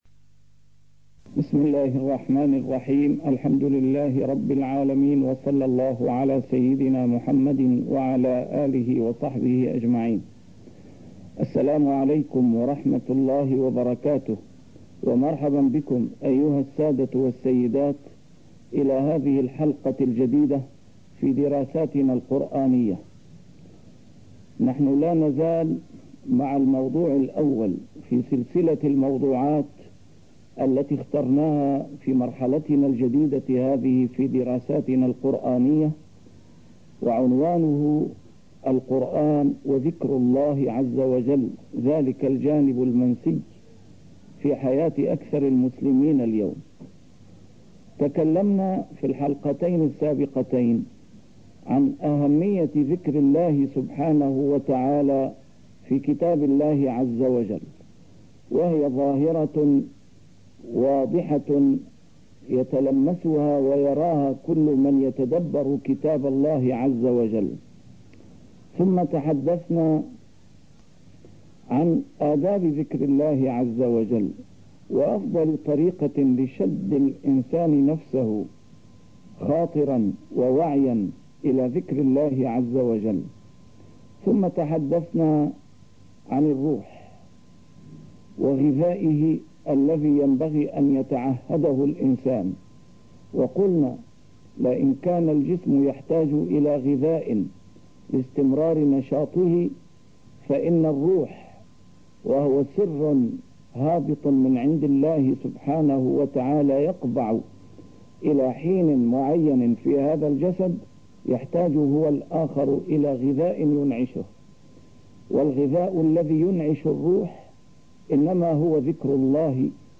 A MARTYR SCHOLAR: IMAM MUHAMMAD SAEED RAMADAN AL-BOUTI - الدروس العلمية - دراسات قرآنية - القرآن وذكر الله عز وجل ذلك الجانب المنسي في حياة الإنسان + القرآن والعلم وأهميته وأثره في كتاب الله عز وجل